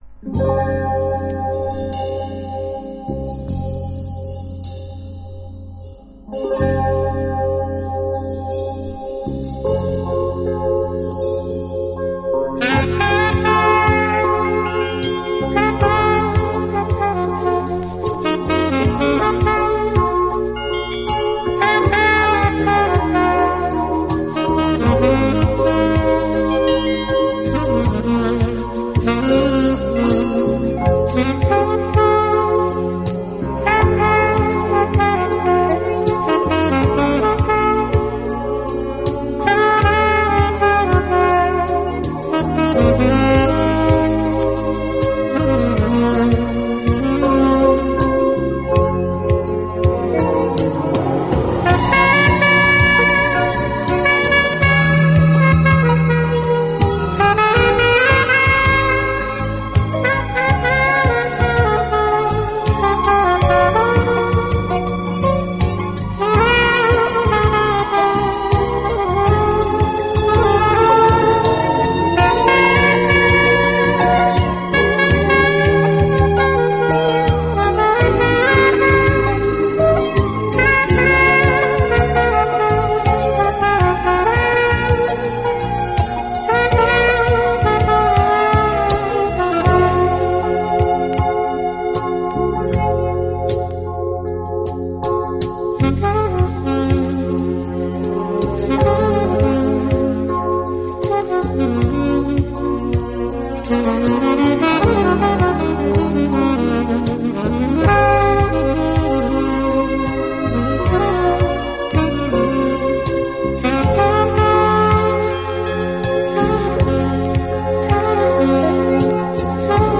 melodija__oseni_-_saksofon_z3.fm_.mp3